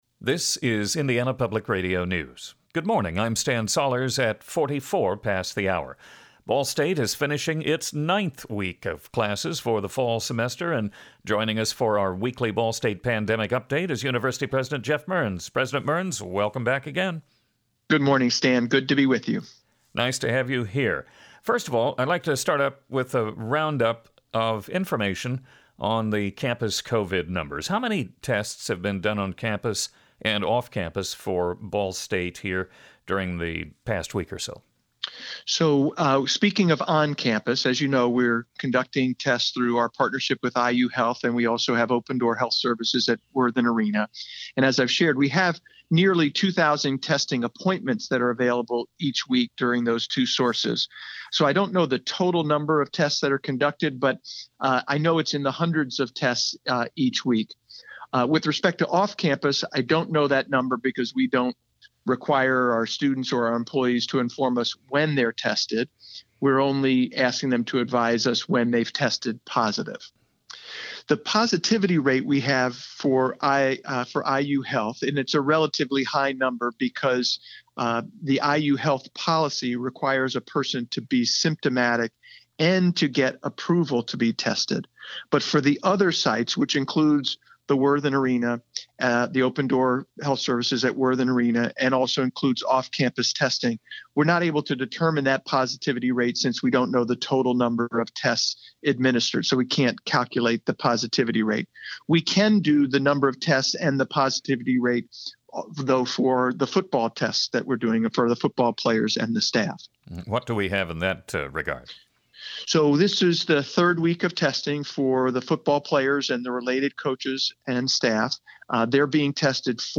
Ball State University President Geoff Mearns, in IPR’s weekly Ball State Pandemic Update, updates the university’s COVID-19 statistics and talks BSU and MAC football. He also tells the backstory on how and why David Letterman and former Colts quarterback Peyton Manning were on campus this week. You can hear the full interview on the audio app, below: